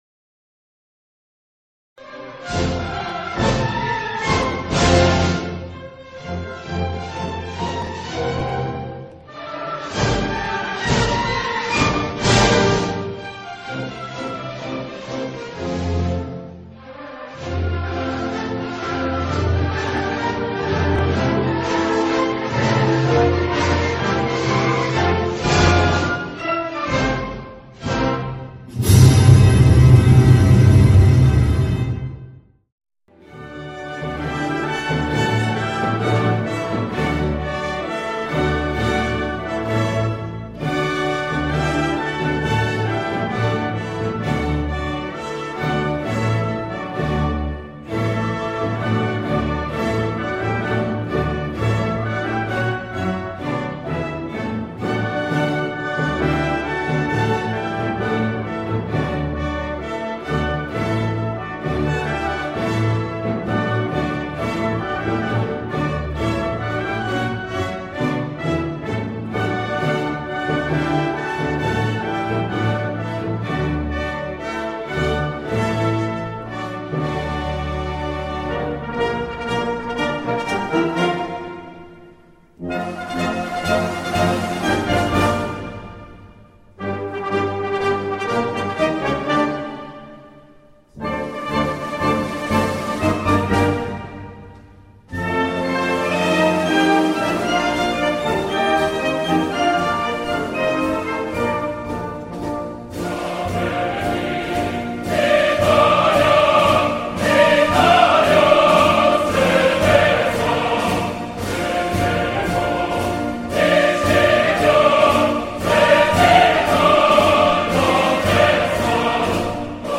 Inni
Audio mp3 – Anno sociale 2024/2025 inni Brasiliano, Europeo, Italiano ( cantato)